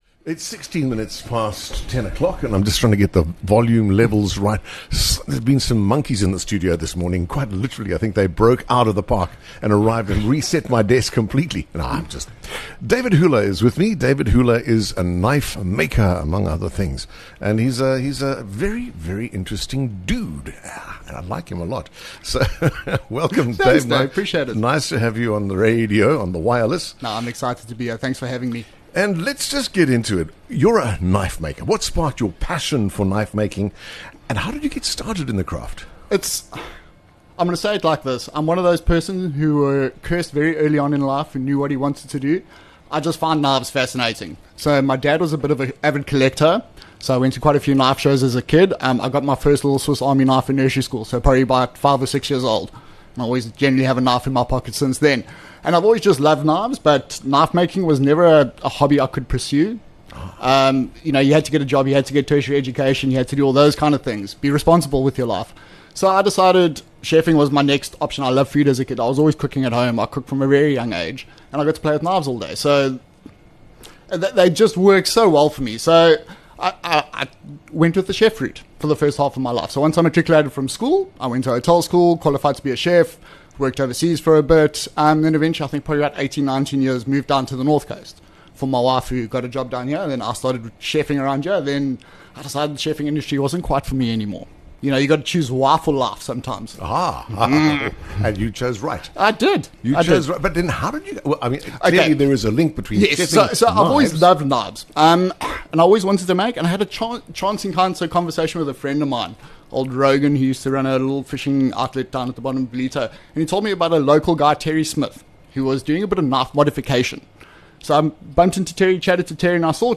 Whether it’s your favourite songs, meaningful milestones, or the moments that shaped you, come and share them live on air.